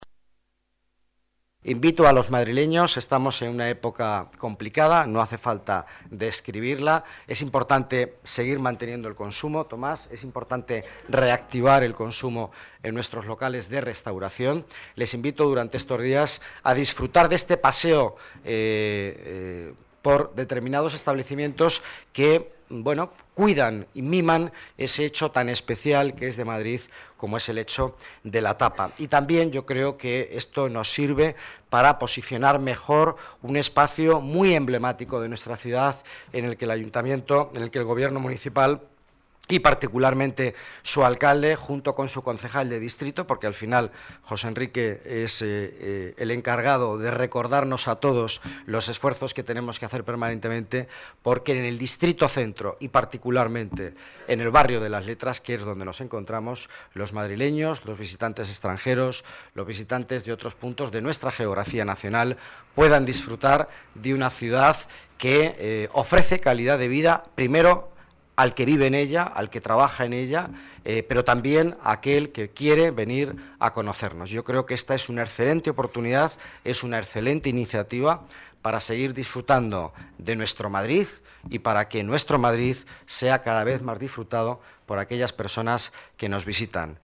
Nueva ventana:Declaraciones de Miguel Angel Villanueva sobre tapas